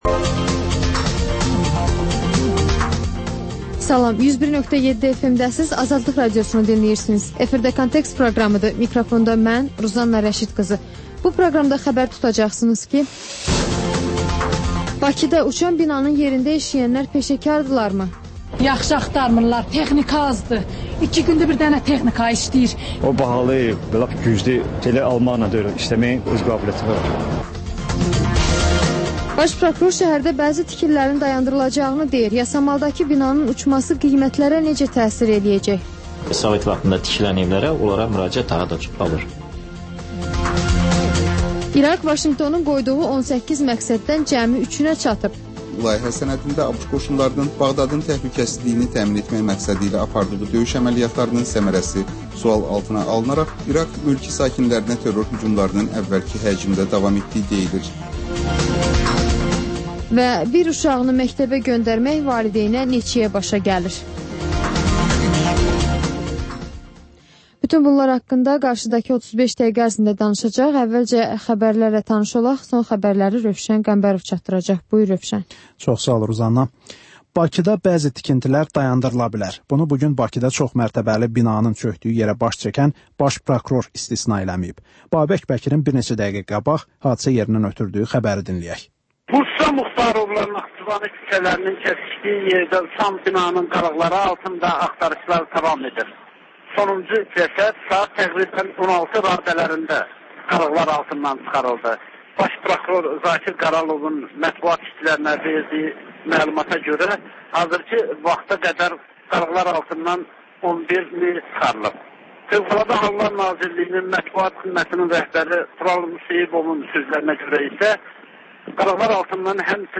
Xəbərlər, müsahibələr, hadisələrin müzakirəsi, təhlillər, sonra TANINMIŞLAR rubrikası: Ölkənin tanınmış simalarıyla söhbət